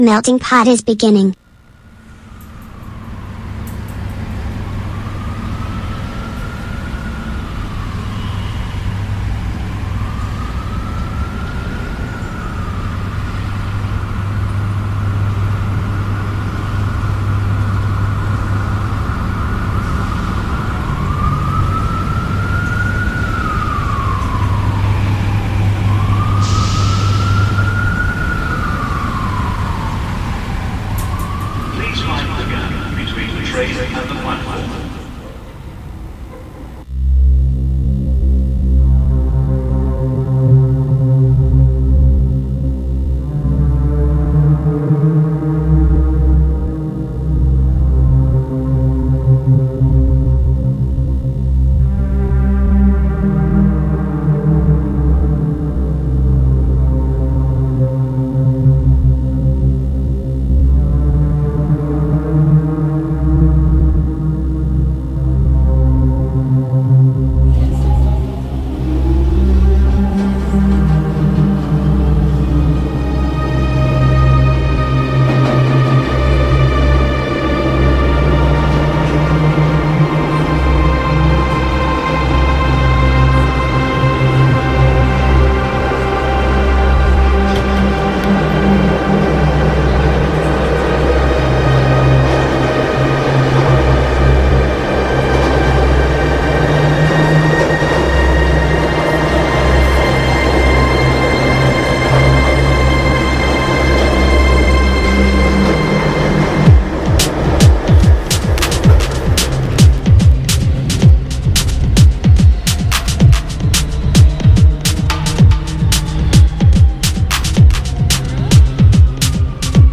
Nell’ultima puntata di Meltingpot la 27, andata in onda mercoledì 30 luglio su Radio Città Aperta, abbiamo cercato di tenere insieme le tante crepe di questo presente. Morti sul lavoro, crisi ambientale, genocidio a Gaza: notizie che spesso restano ai margini del flusso mainstream, ma che ci riguardano da vicino. Le abbiamo raccontate, commentate e intrecciate alla musica, perché crediamo che informare significhi anche resistere.